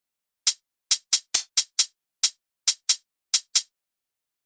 HiHat Before